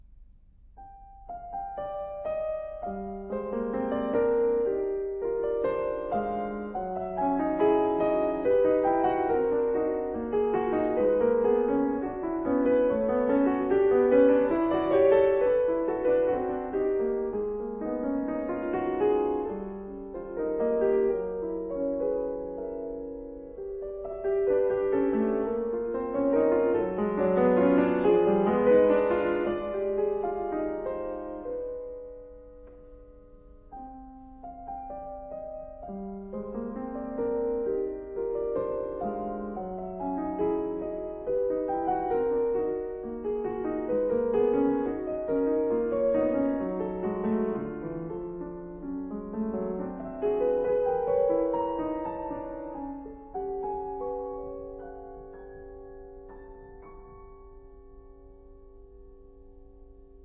她呈現了一種百年累積後，又開了小花的香味。